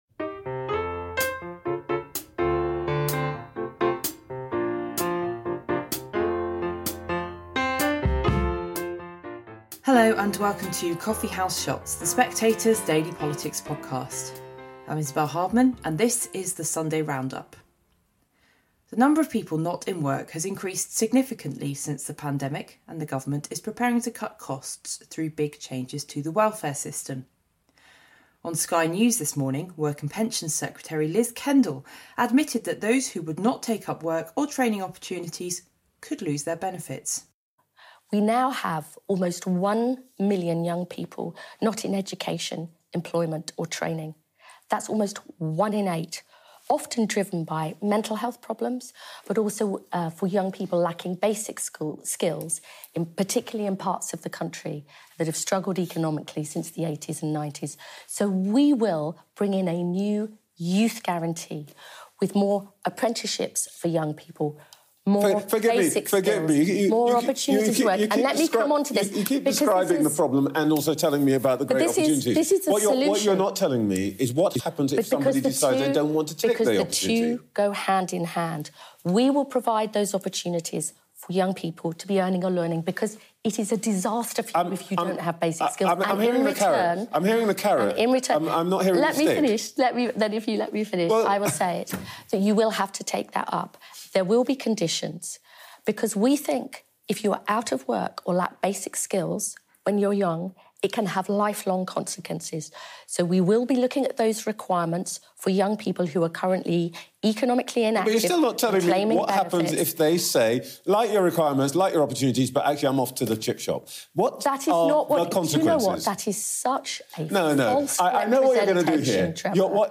Isabel Hardman presents highlights from Sunday morning’s political shows.
The vote on the Assisted Dying Bill is approaching. Work and Pensions Secretary Liz Kendall gives her view, and Belgian health minister Frank Vandenbroucke argues that assisted dying doesn’t lead to a ‘slippery slope’. We also hear about the government’s upcoming changes to the welfare system, whether the UK should arrest Netanyahu if he comes to the country, and if there are any limits to France’s support for Ukraine.